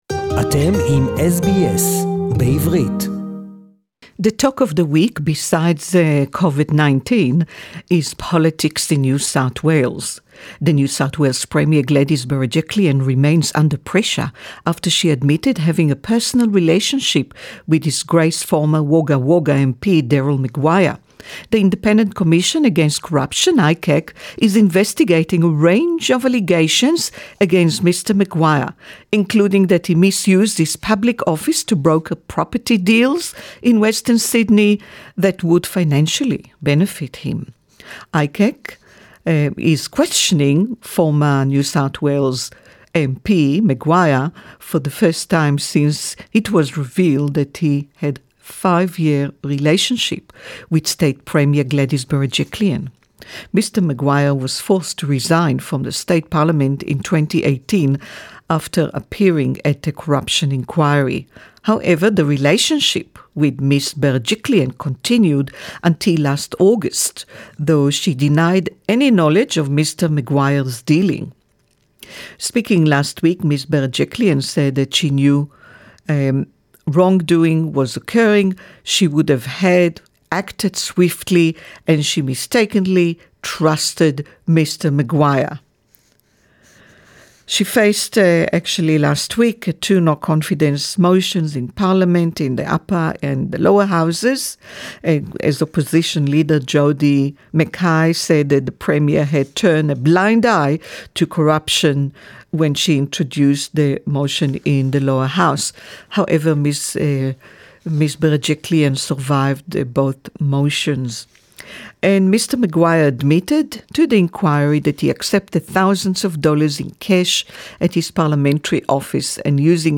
NSW Premier Gladys Berejiklian:"I've done nothing wrong" (English report)